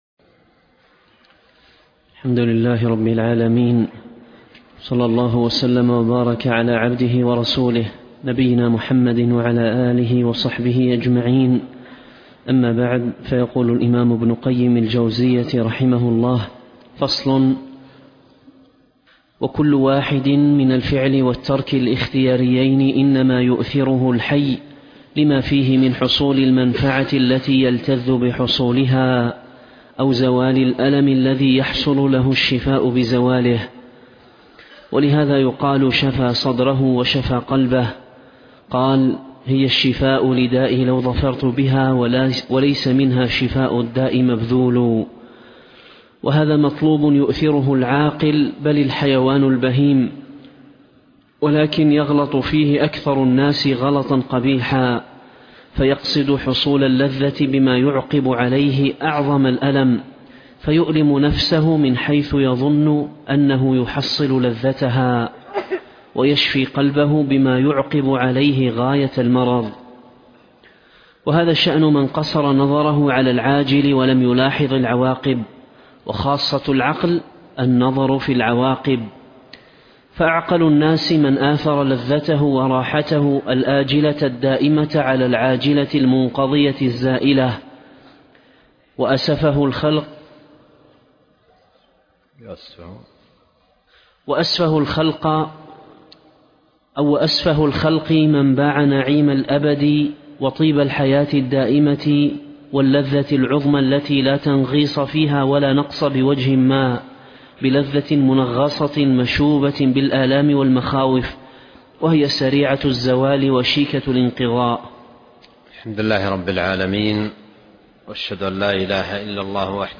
الدرس 63